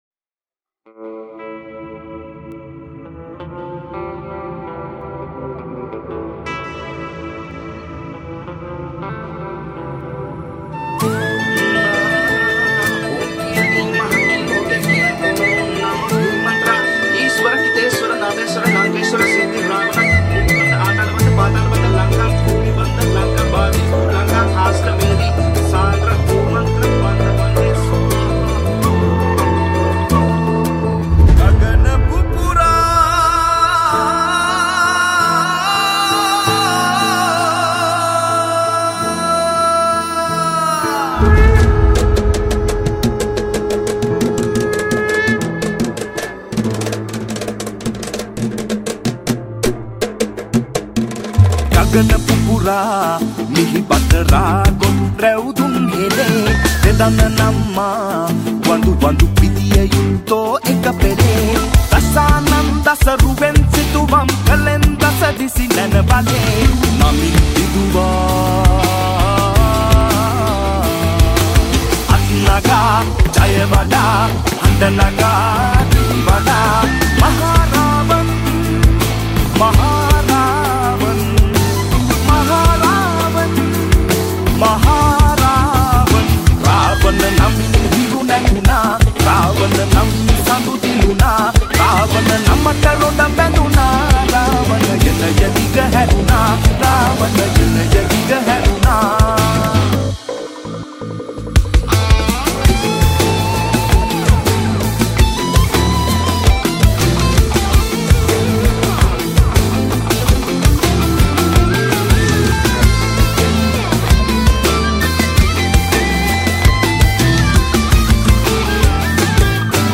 Theme Song
Guitars
Percussion
Flutes